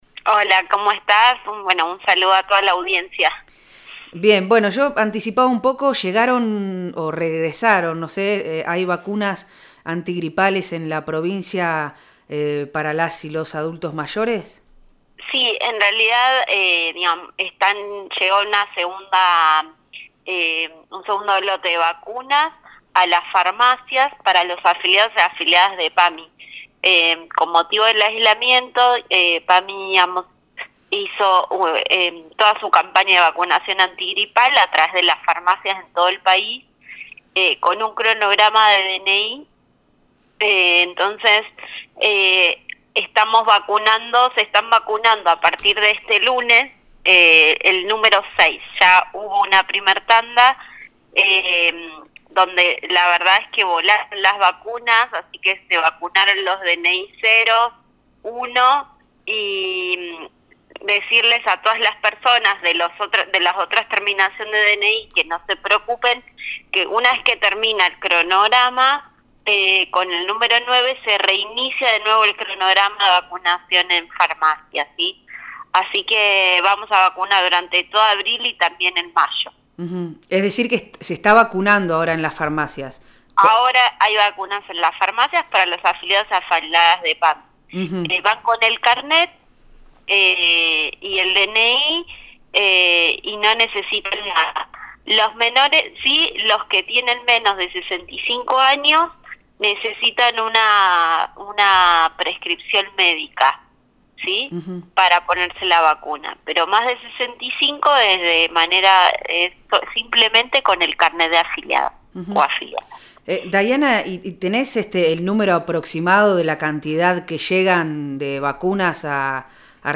en diálogo con Proyecto Erre explicó el sistema de distribución de las vacunas, mencionó la cantidad que se entrega en todo el país e informó sobre la modalidad de aplicación en el marco de la pandemia por Coronavirus.